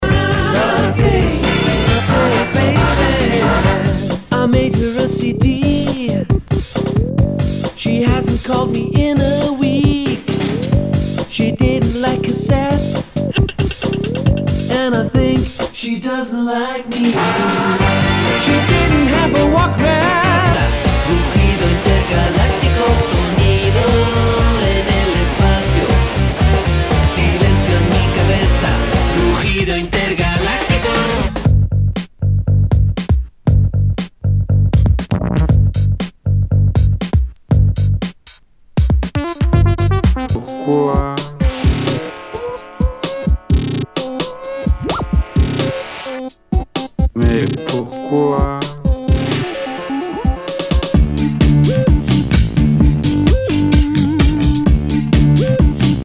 Nu- Jazz/BREAK BEATS
ナイス！ファンキー・ブレイクビーツ！